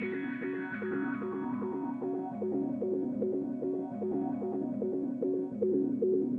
guitar.wav